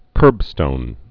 (kûrbstōn)